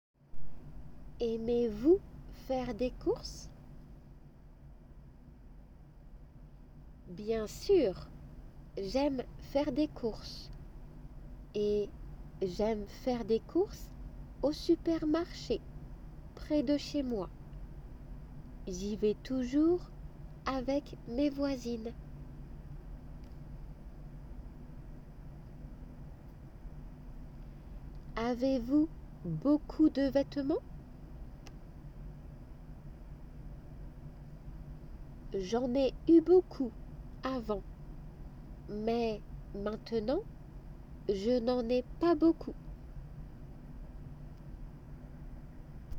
2級の2次試験― natifによる日常の会話内容